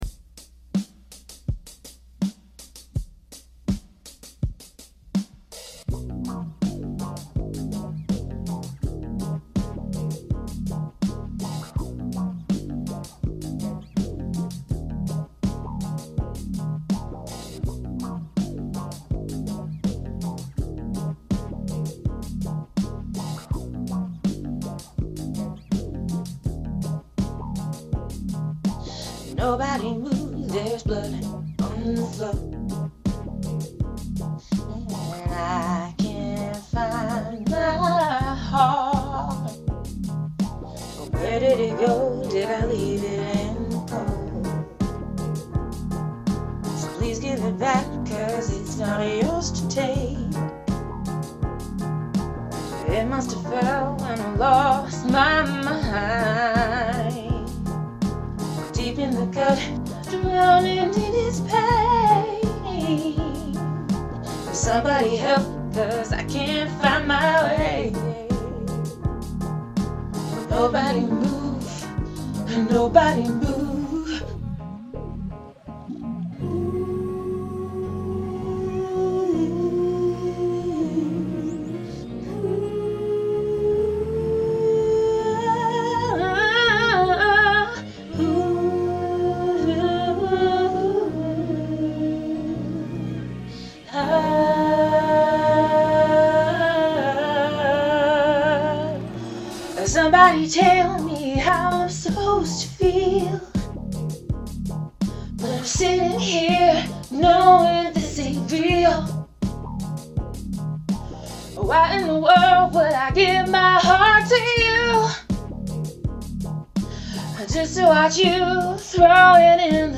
Funk Cover